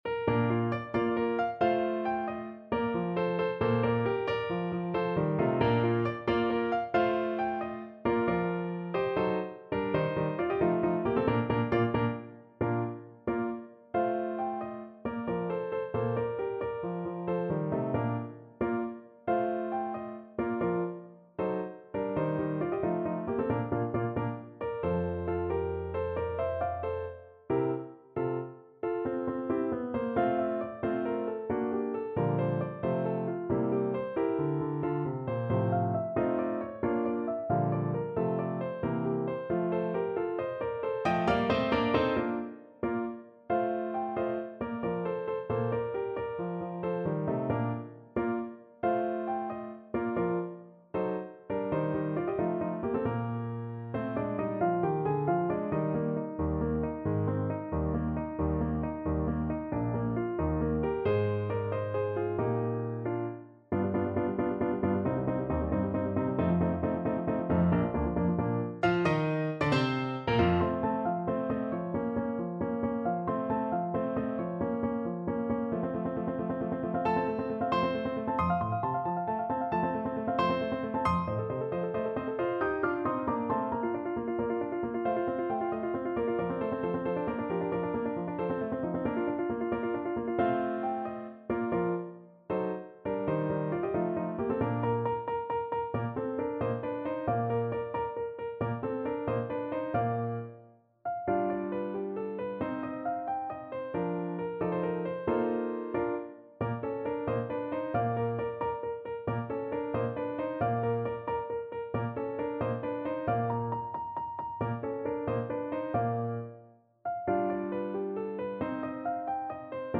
Play (or use space bar on your keyboard) Pause Music Playalong - Piano Accompaniment Playalong Band Accompaniment not yet available reset tempo print settings full screen
Bb major (Sounding Pitch) (View more Bb major Music for Mezzo Soprano Voice )
. = 90 Allegretto vivace
Classical (View more Classical Mezzo Soprano Voice Music)